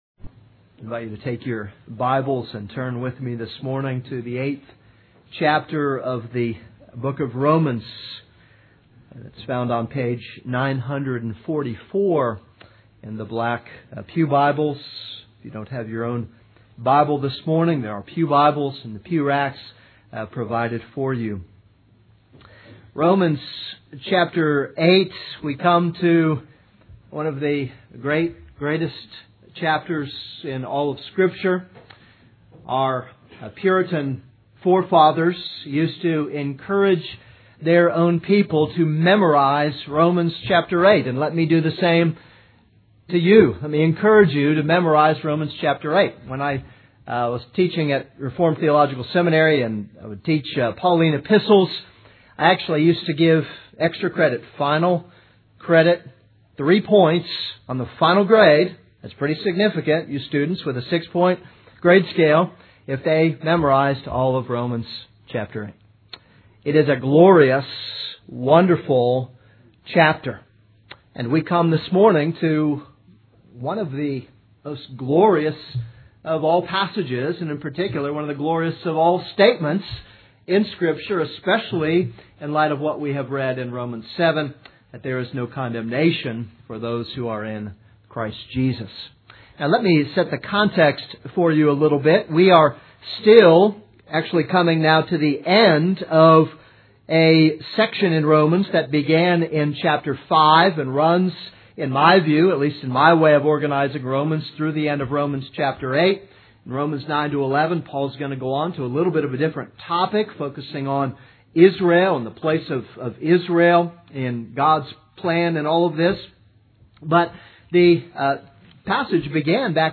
This is a sermon on Romans 8:1-4.